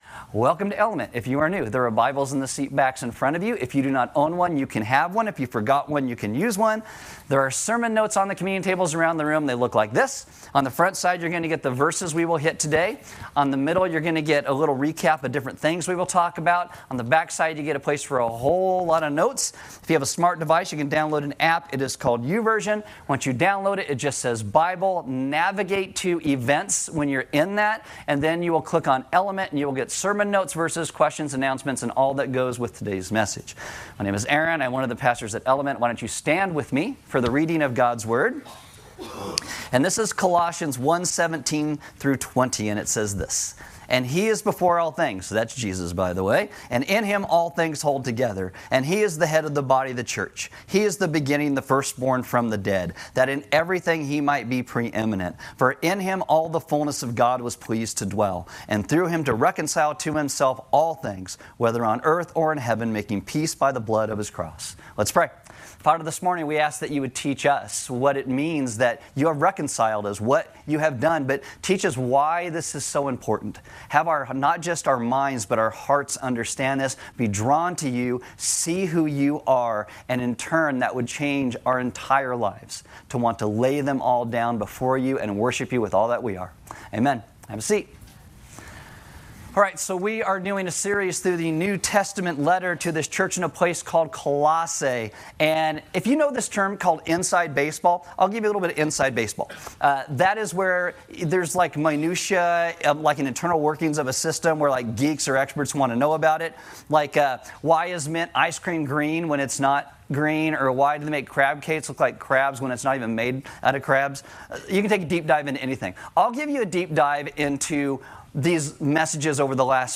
A message from the series "Colossians." This is our second week looking specifically at what it means that Jesus is over all things (the Creator, the supreme, the highest).